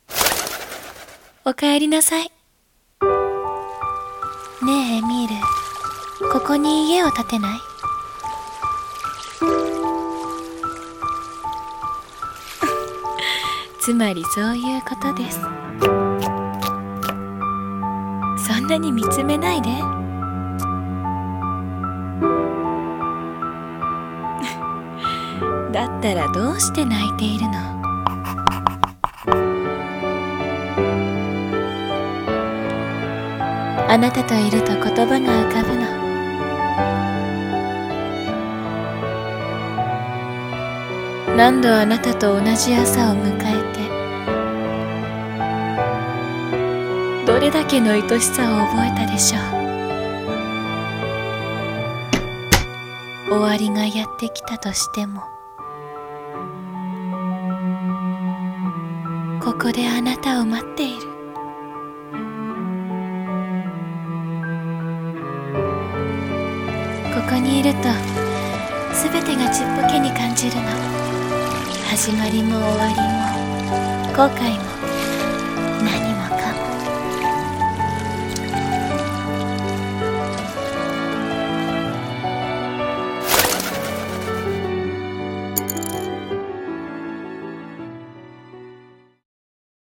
声劇】そして、還る